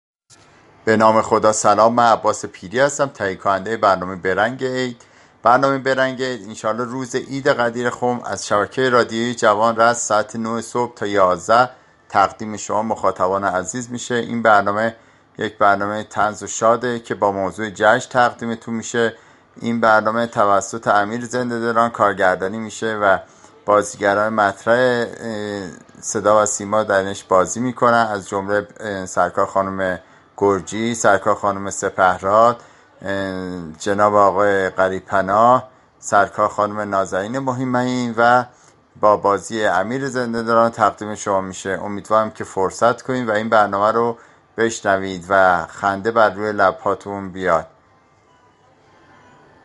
«به رنگ عید» با متن‌ها، گزارش و بخش‌های شاد طنز و نمایش، همراه با شنوندگان شبكه جوان، عید غدیر خم را جشن می‌گیرد.